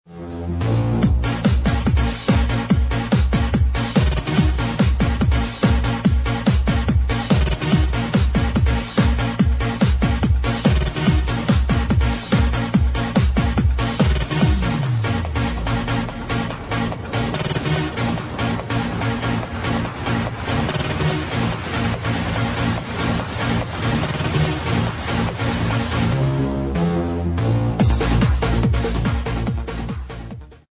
from the 2006 trance energy